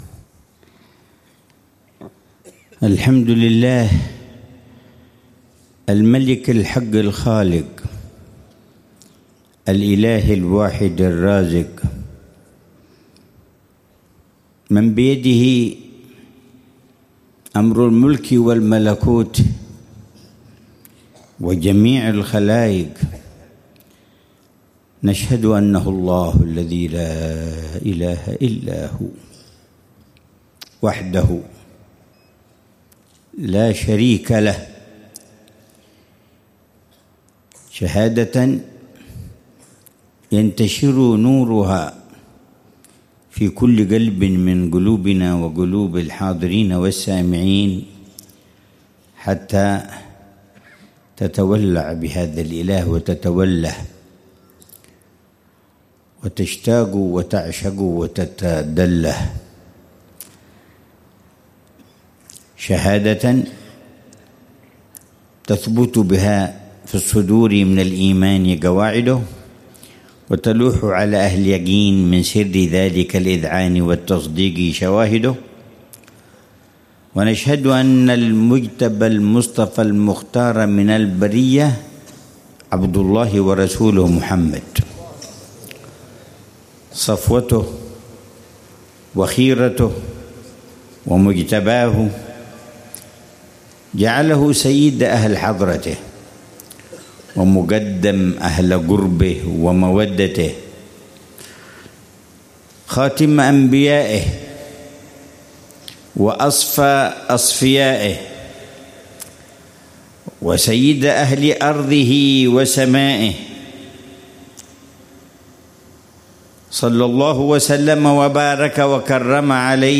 محاضرة العلامة الحبيب عمر بن محمد بن حفيظ في المجلس الثالث من مجالس الدعوة إلى الله في شعب النبي هود عليه السلام، ضمن محاور (تقوية الإيمان وتقويم السلوك) ، ليلة الجمعة 8 شعبان 1446هـ بعنوان: